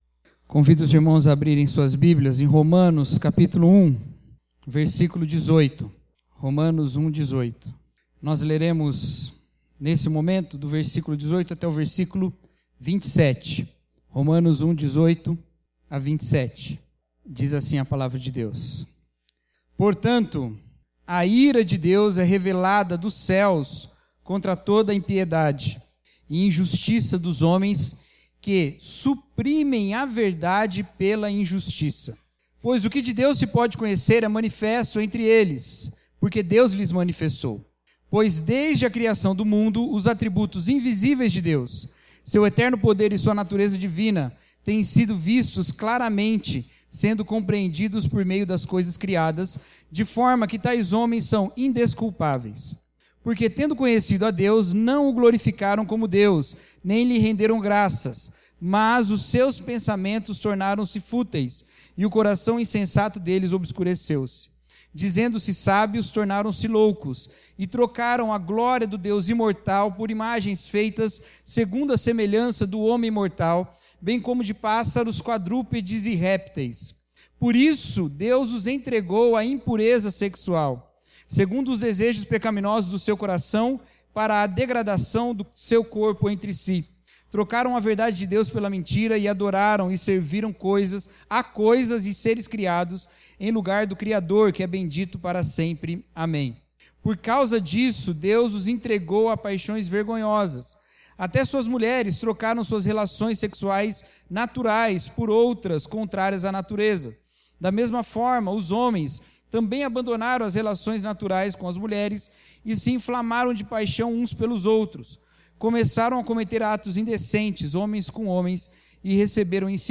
[Série: O Evangelho de Deus] Mensagem – Culpados Pela Natureza – Igreja Batista Vida Nova